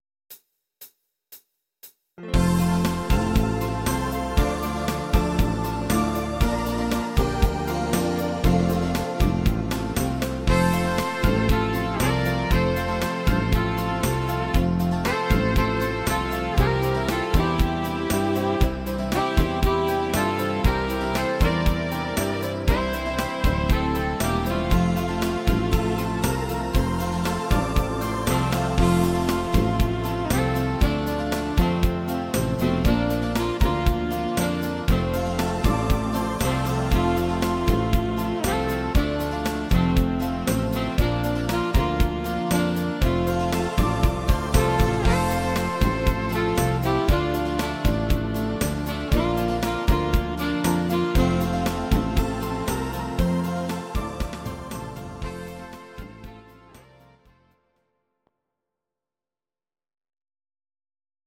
Audio Recordings based on Midi-files
instr. Saxophon